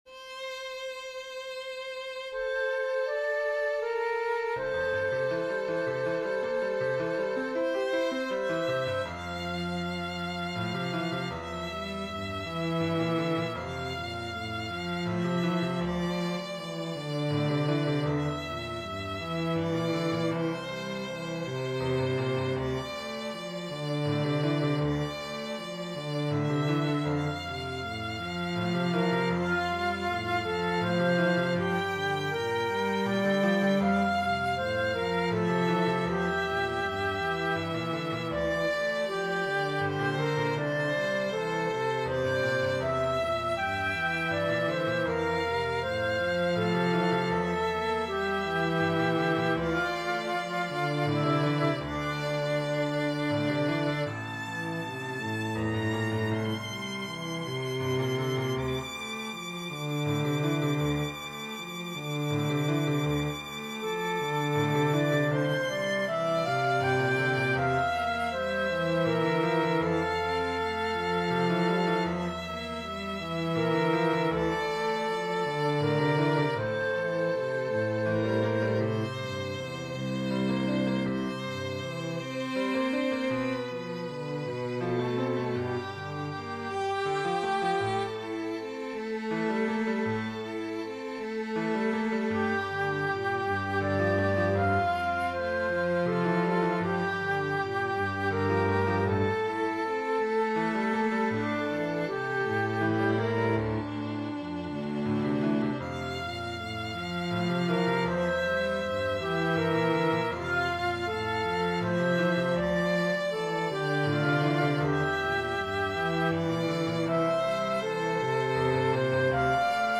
Acceptance - Chamber Music - Young Composers Music Forum
It is part of a broader concept: a Cycle of Death, in which I explore the theme of mortality across three pieces: Denial, Acceptance, and Rest. Using the Dies Irae as a harmonic backbone.
In this piece, each instrument has a distinct role: The piano represents the truth of death that must be accepted. The flute embodies the human conversation with oneself: longing, hoping, and ultimately accepting. The violin and cello symbolize the path toward acceptance, guiding the listener through the journey.